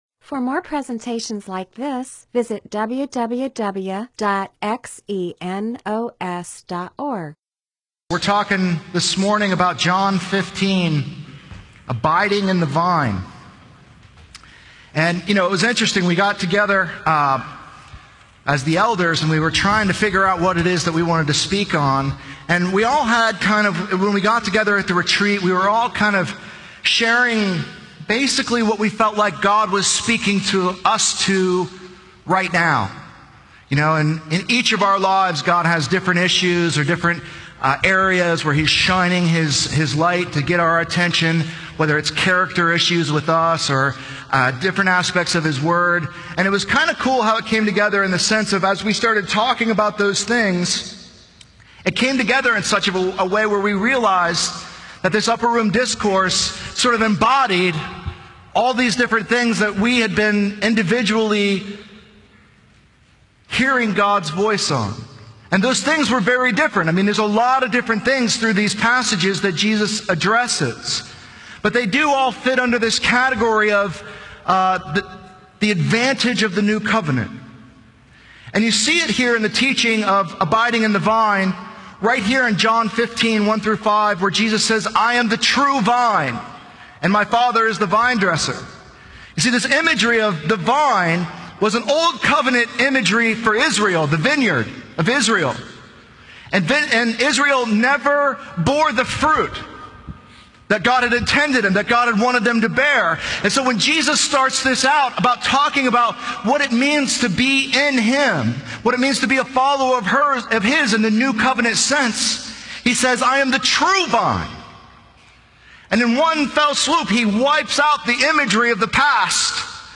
MP4/M4A audio recording of a Bible teaching/sermon/presentation about John 15:1-5.